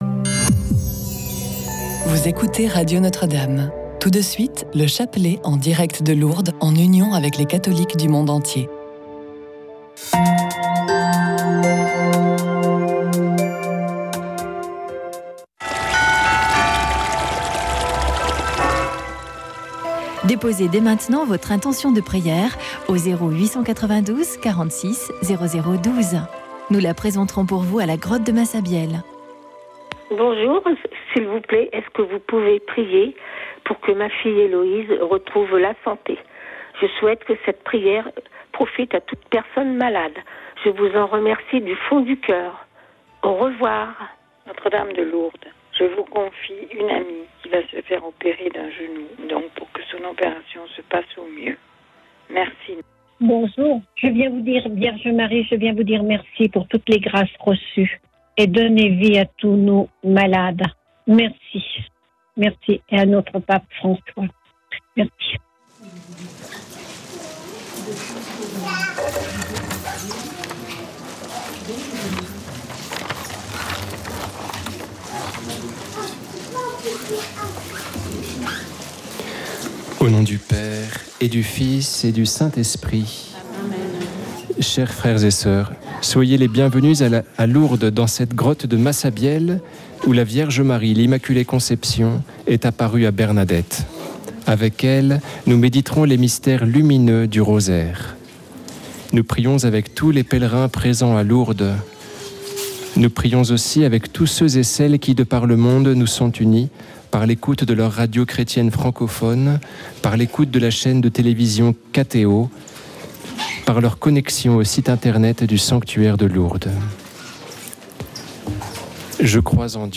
Chapelet de Lourdes - 05.03.2025